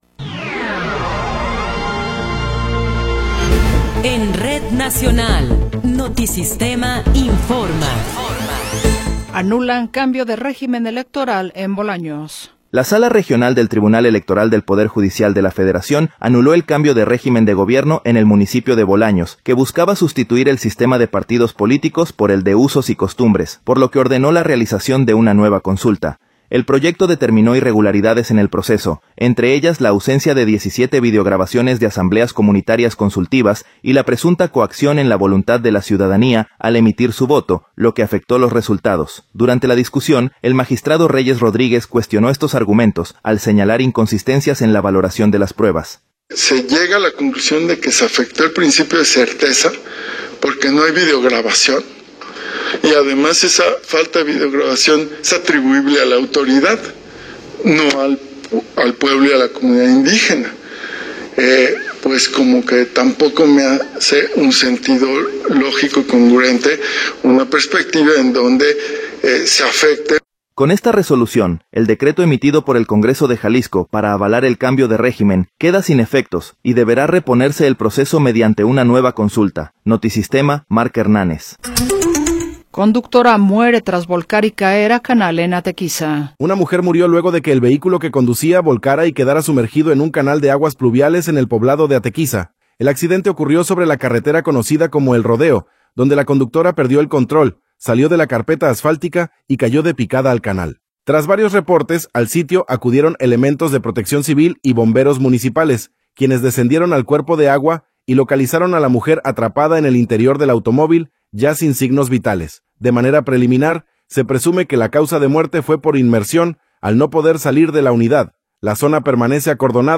Noticiero 16 hrs. – 22 de Abril de 2026